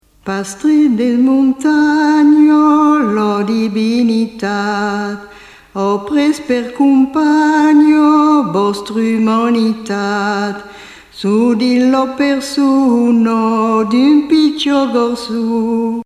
Origine : Rouergue (Aveyron)
cassette audio